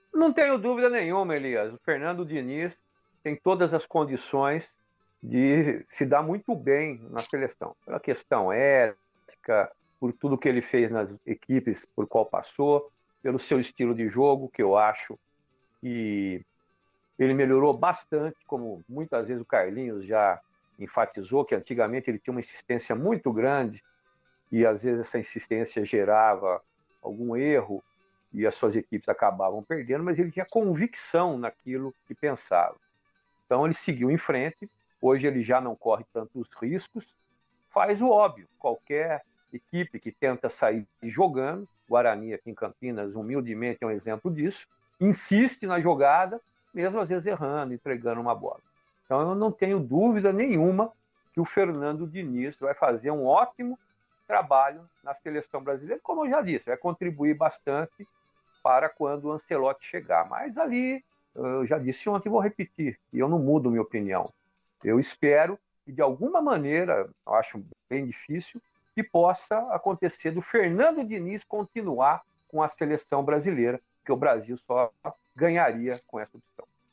Comentaristas da Rádio Brasil analisam as movimentações da janela de transferências